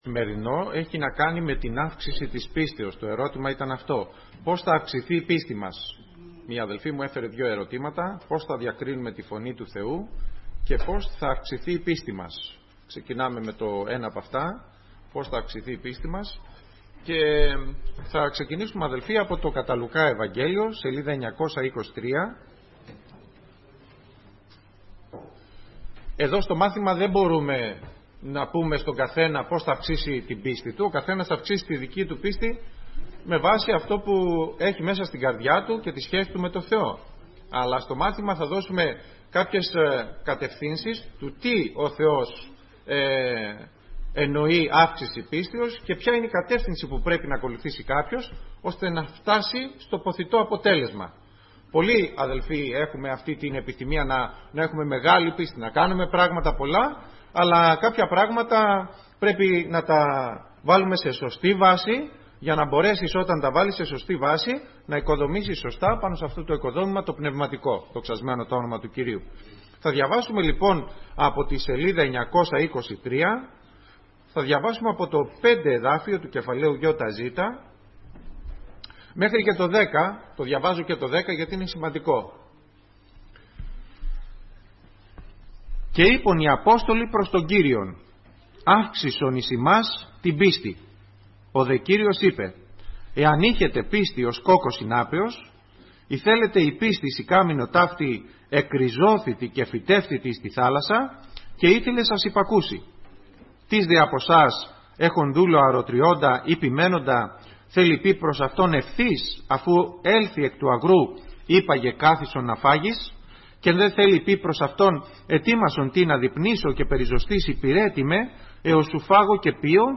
Κηρύγματα